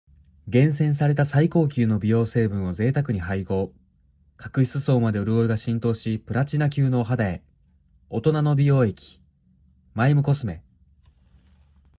年齢 2003年⽣まれ（22歳） ⾝⻑・体重 173cm・60kg サイズ S/27.5 出⾝地 兵庫県 血液型・利き⼿ Ｏ型・右手 趣味 動物園巡り、バスケットボール、野球観戦 特技 バスケットボール 資格・免許 第一種普通自動車免許（MT）、国内旅行業務取扱管理者 ボイスサンプル CM